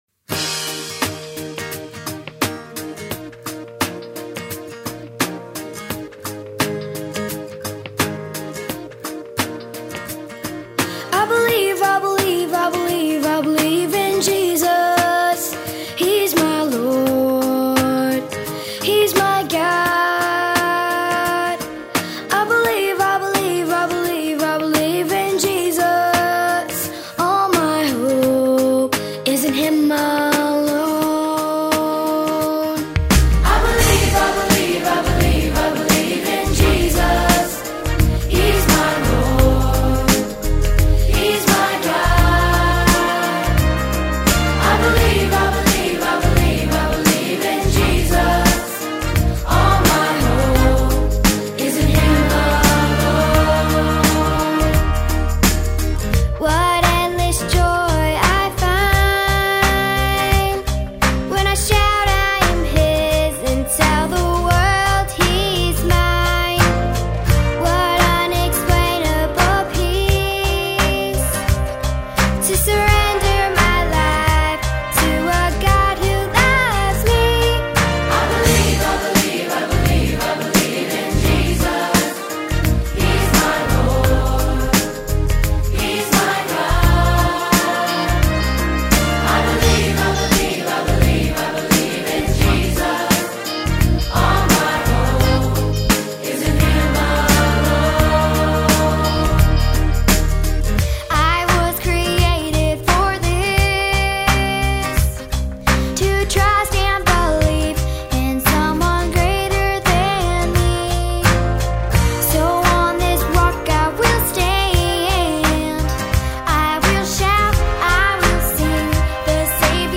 We will be singing the song below for the Confirmation service on April 27th at 3pm. We have a great group of confirmation kids singing solos and this will be such a special time of worship for these kids and their families!